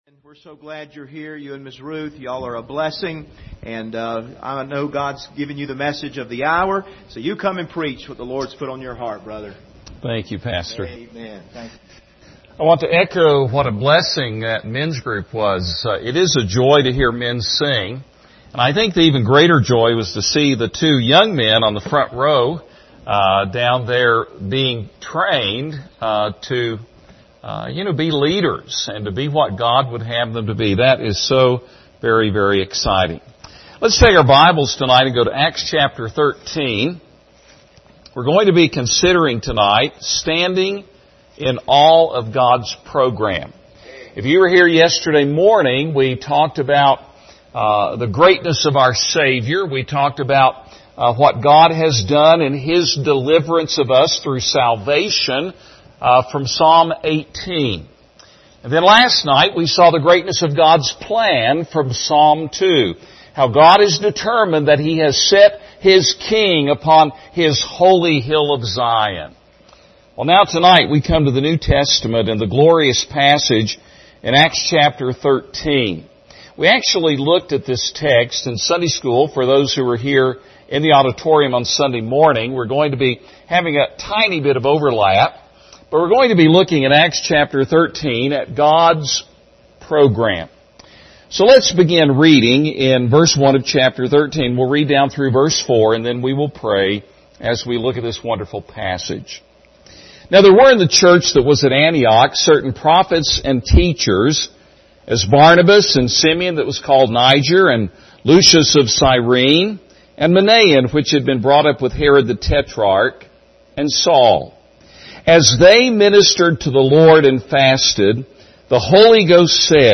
Series: 2021 Missions Conference
Service Type: Special Service